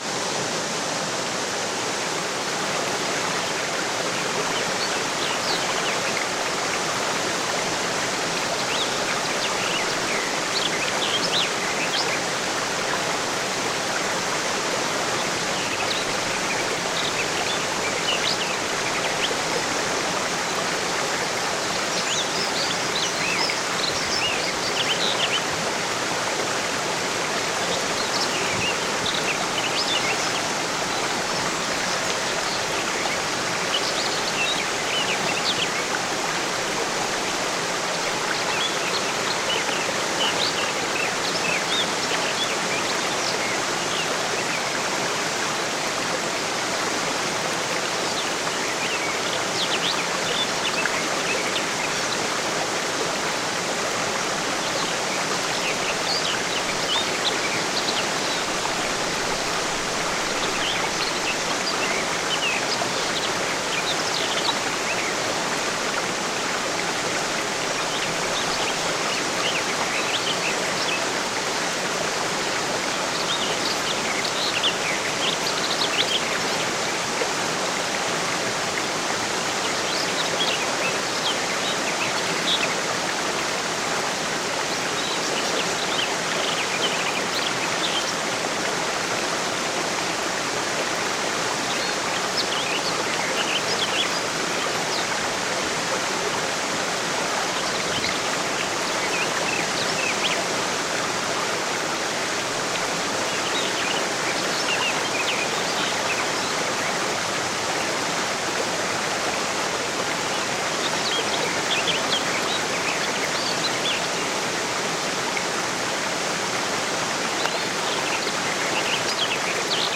HEILENDE KRAFT: Kaskaden-Energie mit Naturrauschen voller Power